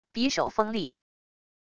匕首锋利wav音频